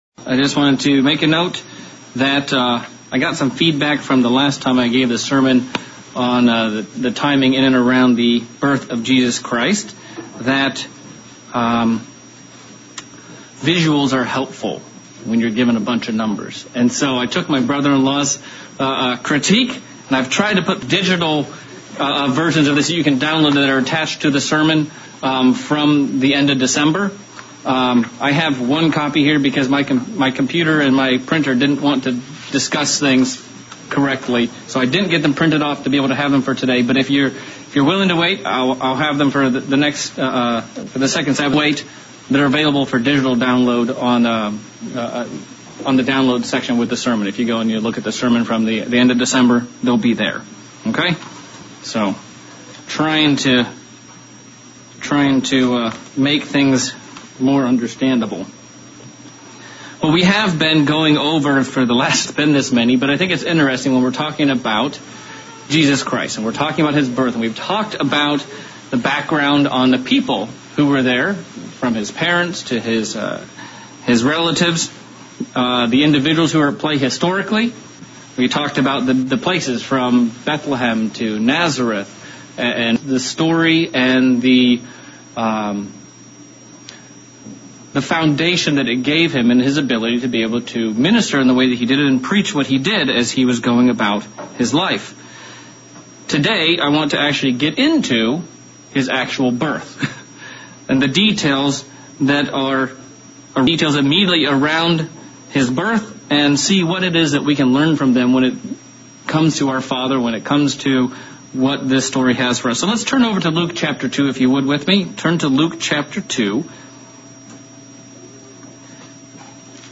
Continuing sermon series on the the Birth of Christ Part 4. Looking at specifics and timings of events around the actual birth of Christ